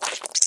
PixelPerfectionCE/assets/minecraft/sounds/mob/spider/say4.ogg at mc116